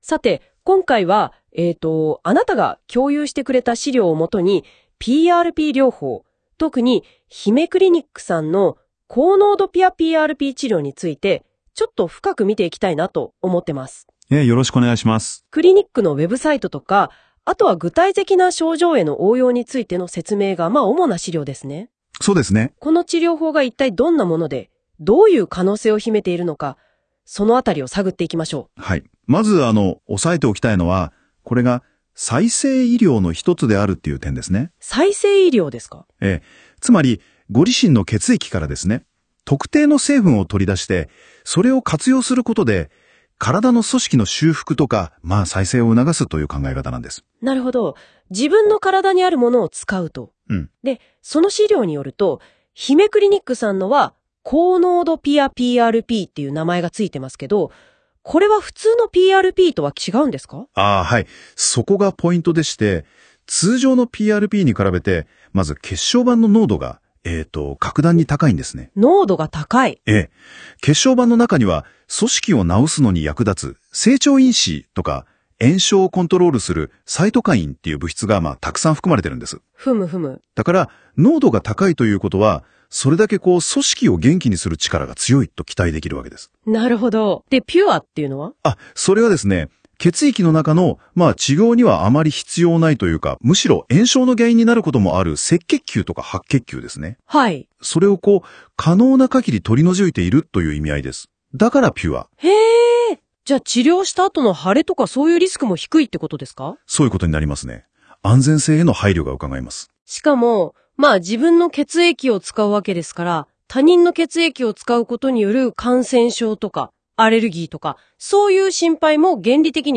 AIによるPRPの説明(音声)